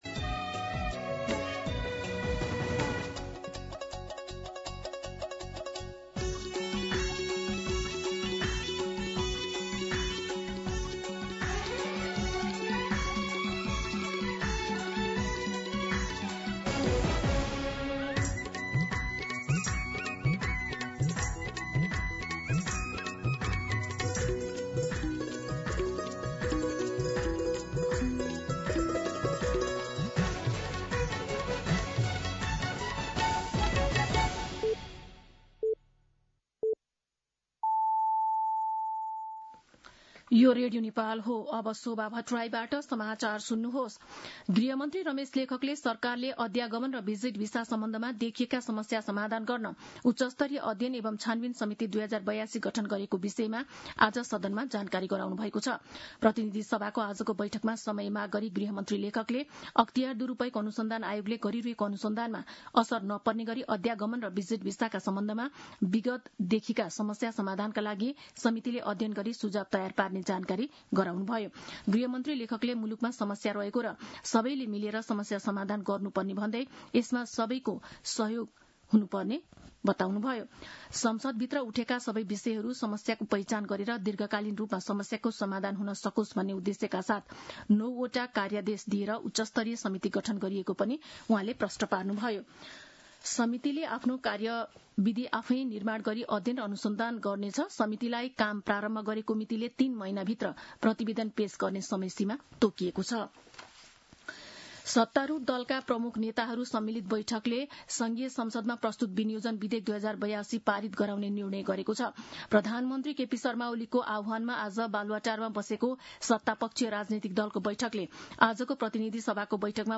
An online outlet of Nepal's national radio broadcaster
दिउँसो ४ बजेको नेपाली समाचार : १० असार , २०८२